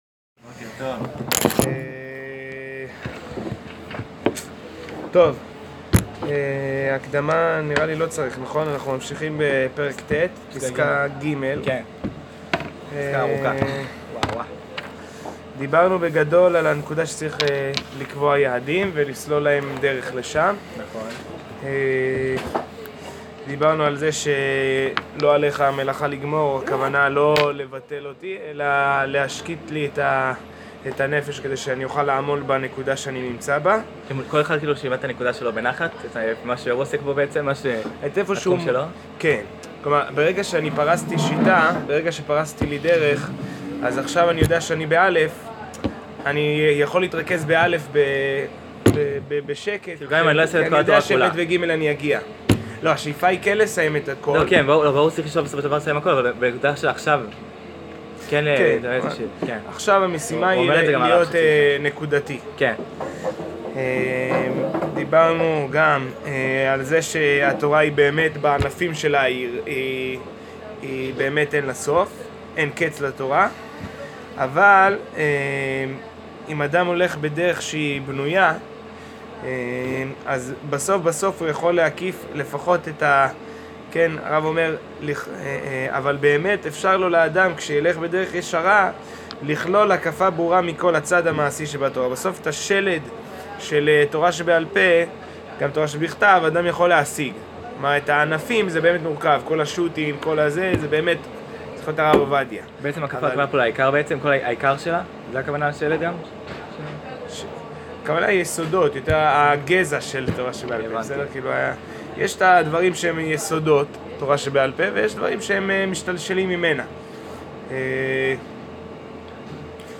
שיעור פרק ט' פס' ג'